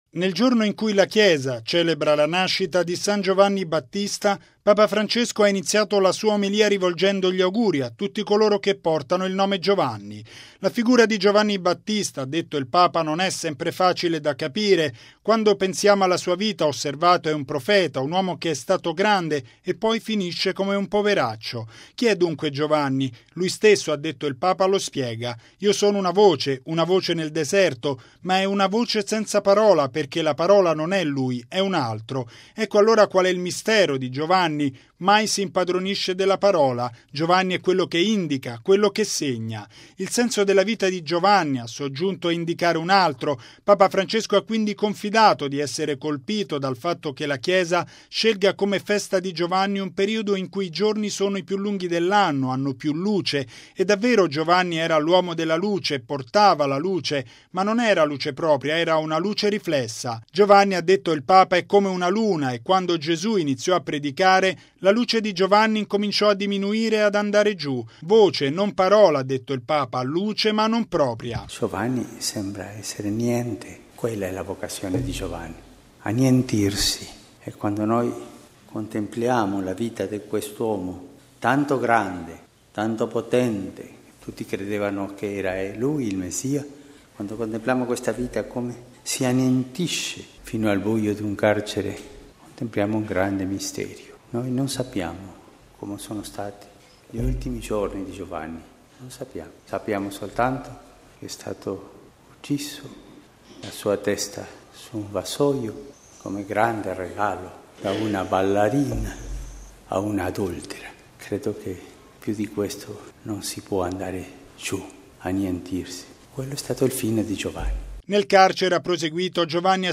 E’ quanto sottolineato da Papa Francesco nella Messa alla Casa Santa Marta, nell’odierna Solennità della Nascita di San Giovanni Battista. Il Papa ha ribadito che la Chiesa non deve mai prendere niente per se stessa, ma essere sempre al servizio del Vangelo.
Il servizio